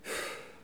pfff-soulagement_05.wav